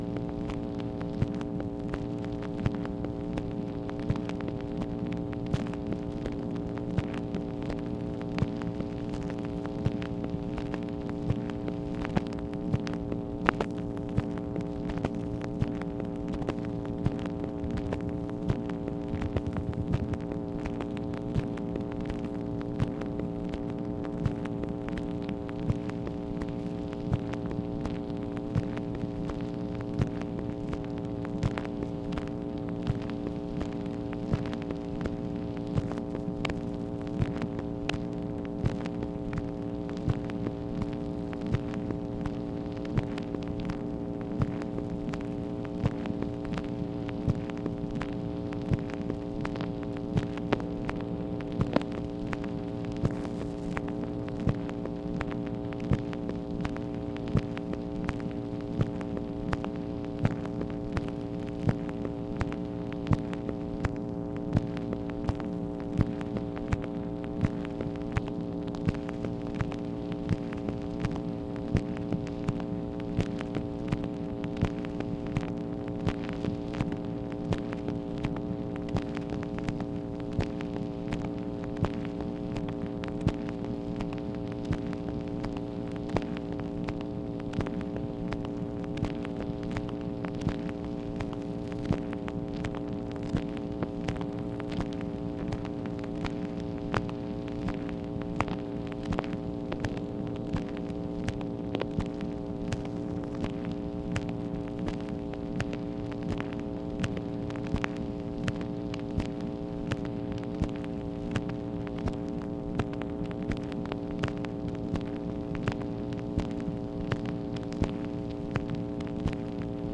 MACHINE NOISE, August 20, 1964
Secret White House Tapes | Lyndon B. Johnson Presidency